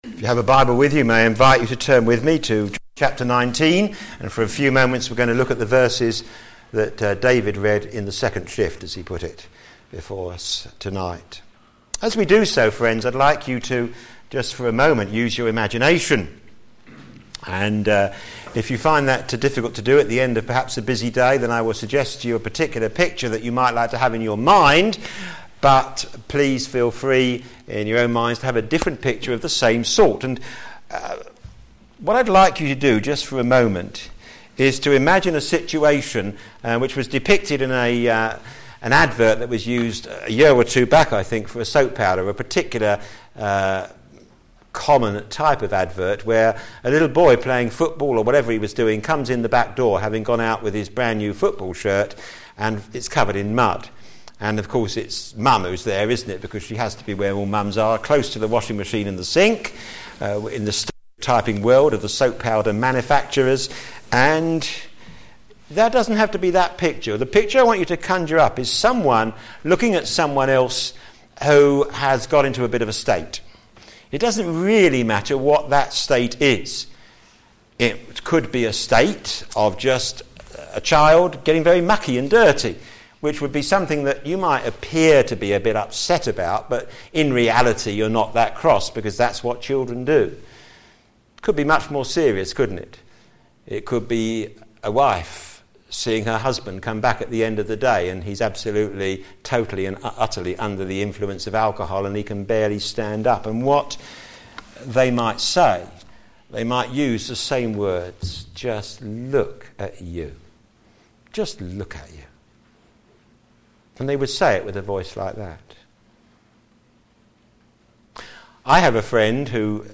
p.m. Service
Jesus handed Over Sermon